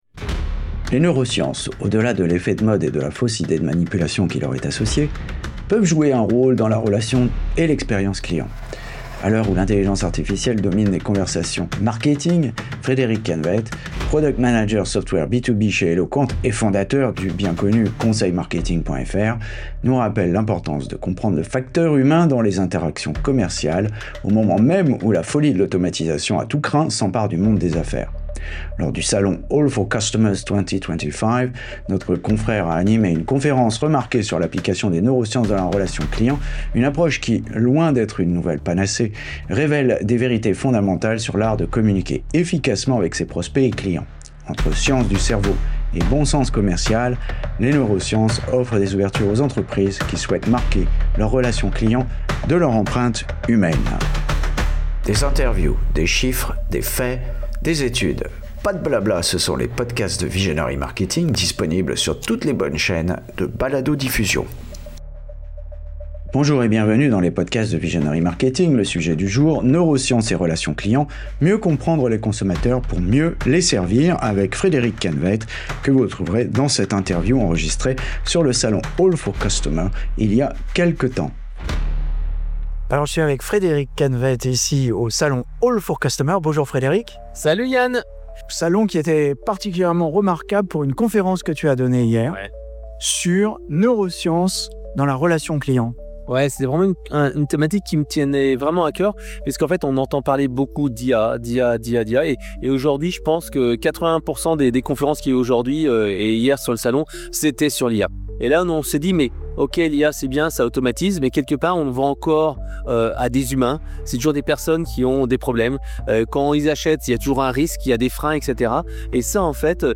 Les neurosciences au secours du marketing... interview par Visionary Marketing